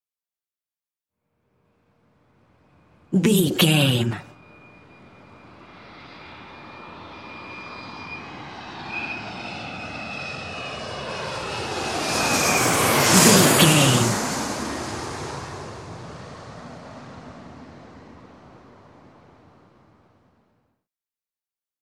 Airplane passby
Sound Effects